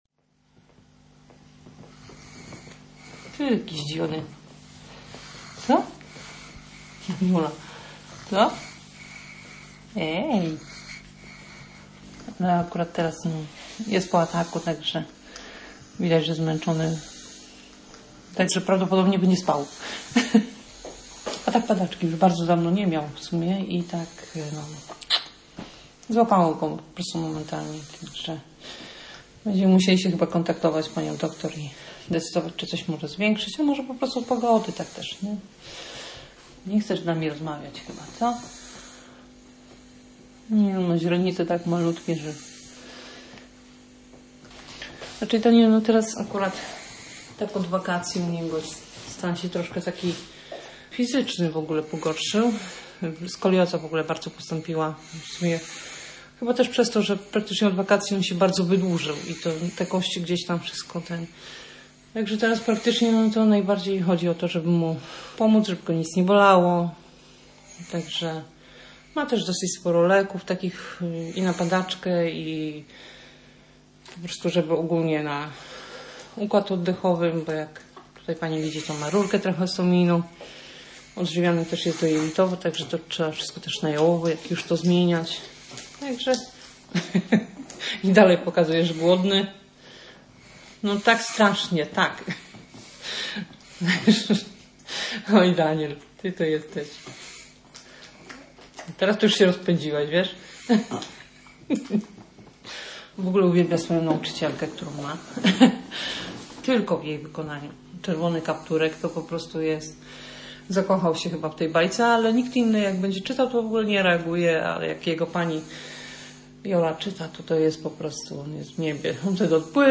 dzieci Fabryka Reportażu matka reportaż śmierć